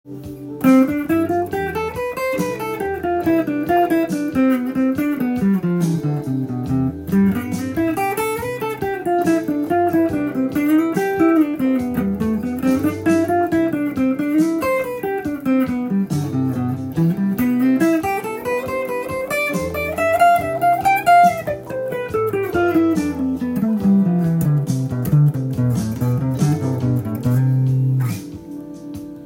１６音符でドレミを適当に弾きます。